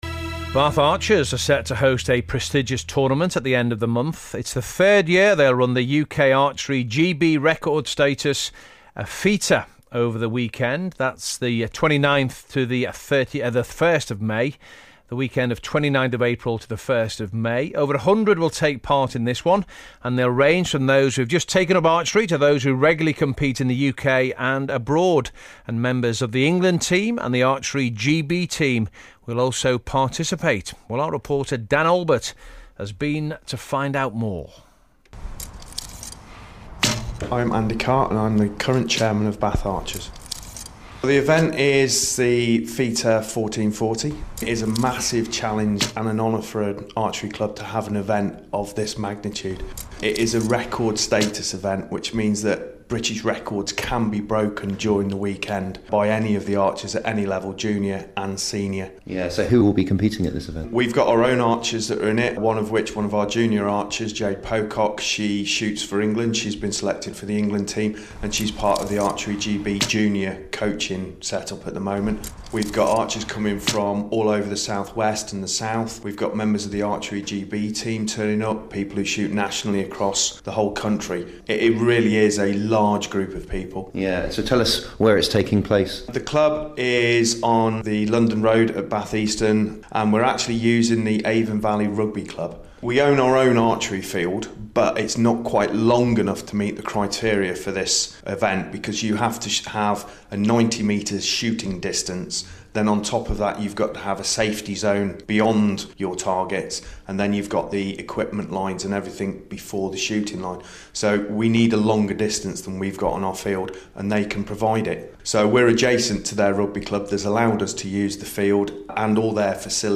BBC Radio Bristol - Bath Archers interview!